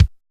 626 KICK 1.wav